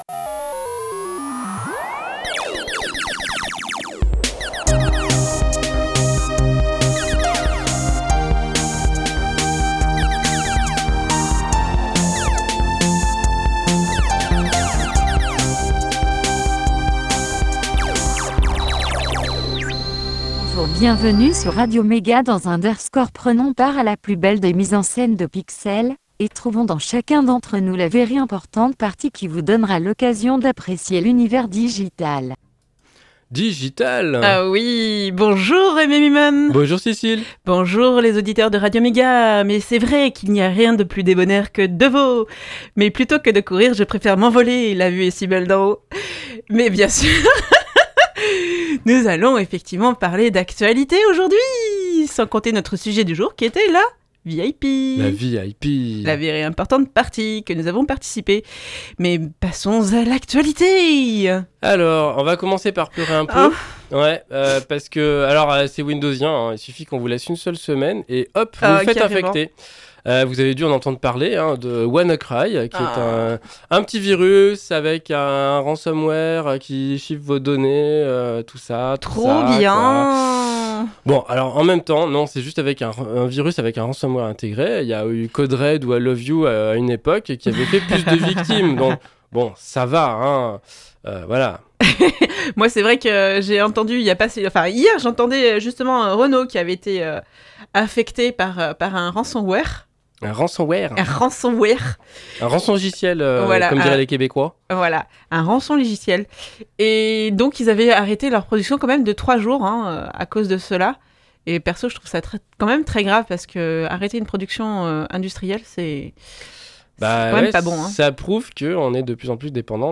Retour sur la VIP De l'actu, une pause chiptune, un sujet, l'agenda, et astrologeek!